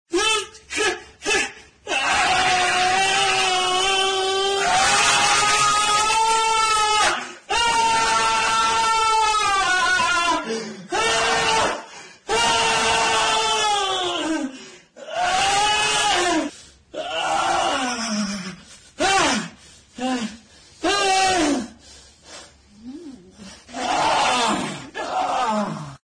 growl.ogg